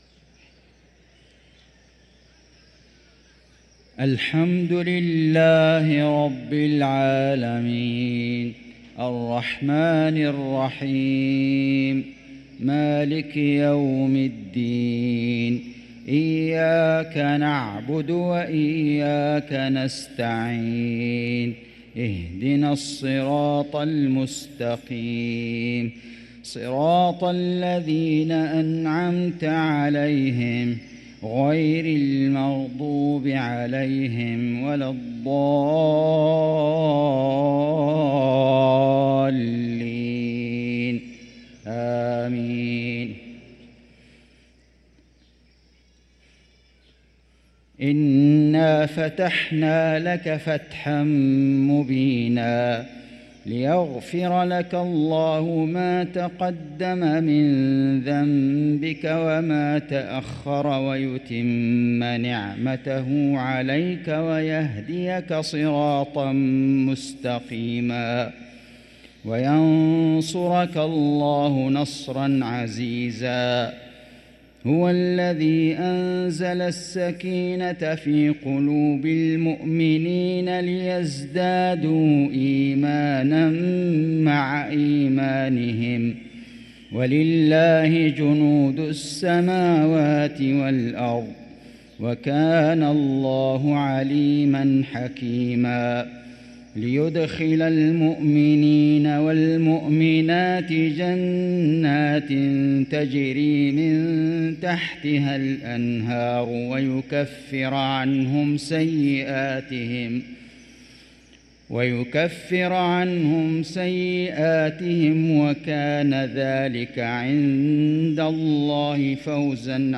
صلاة المغرب للقارئ فيصل غزاوي 16 رجب 1445 هـ